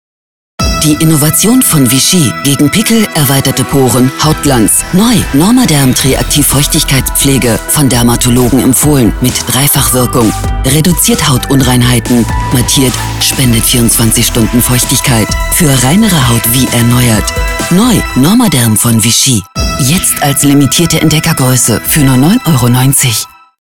variable Stimme von Mittellage über Comic bis tief lasziv
Sprechprobe: Werbung (Muttersprache):